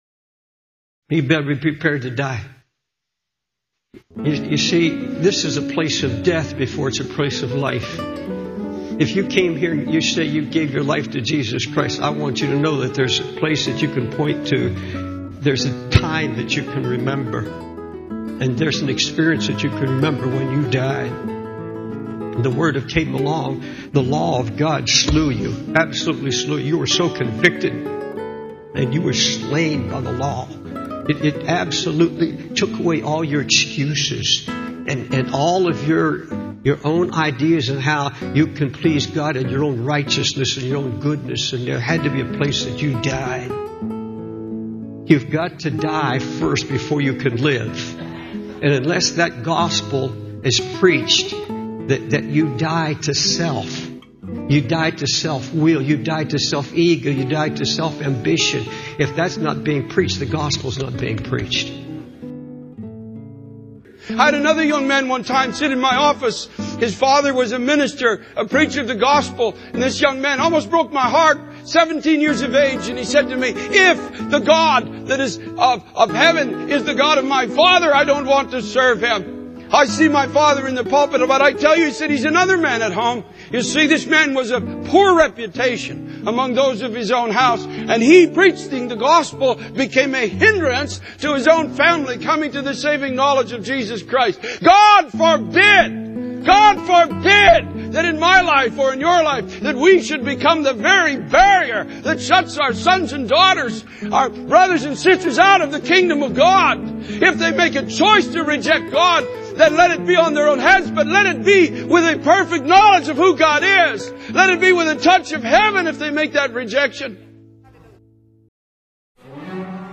The sermon emphasizes the importance of dying to self, living for God, and taking authority over the power of the devil through prayer and faith.